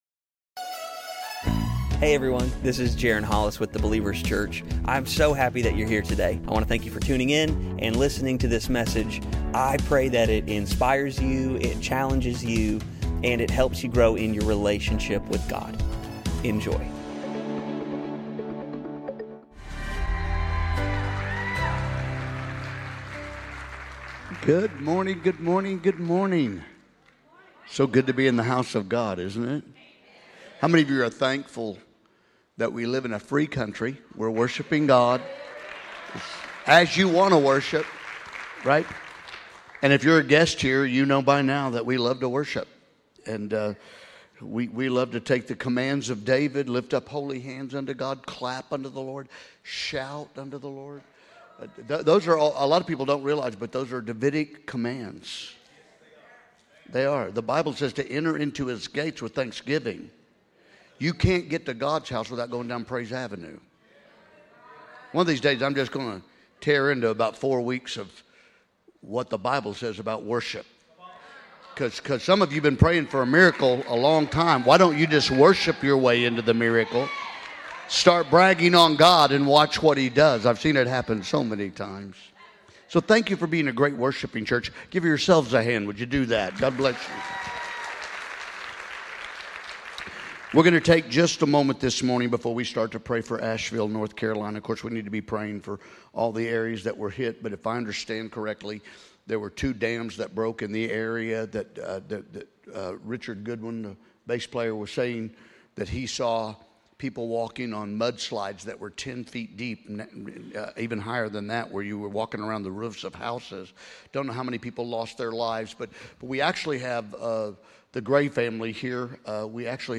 The Believers Church - Sunday Messages